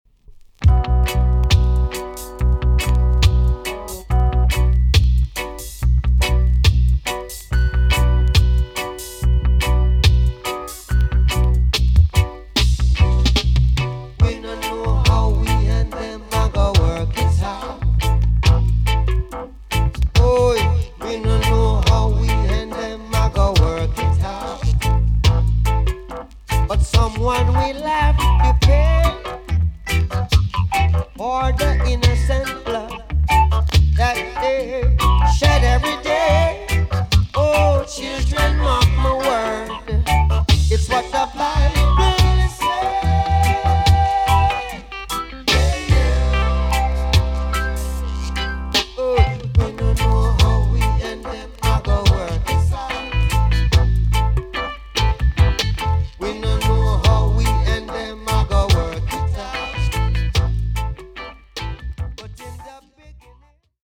TOP >LP >VINTAGE , OLDIES , REGGAE
A.SIDE EX 音はキレイです。